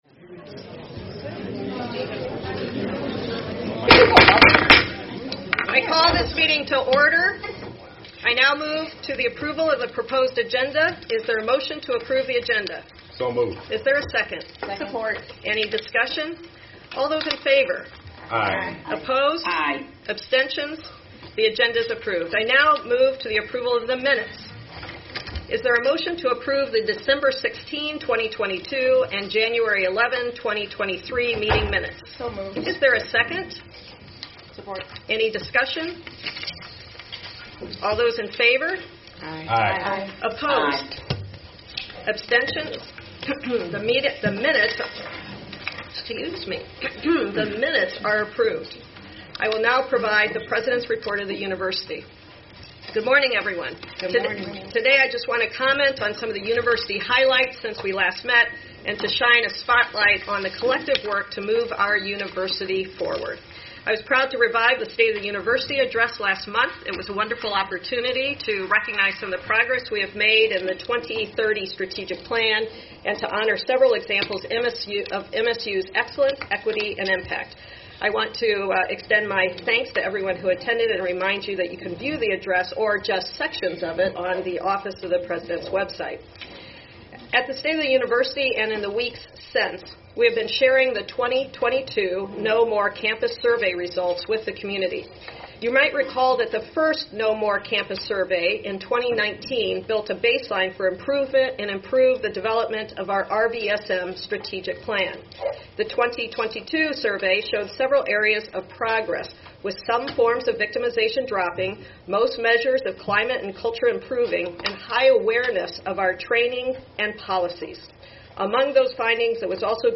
Where: Board Room, 401 Hannah Administration Building
February 10, 2023 Board of Trustees Meeting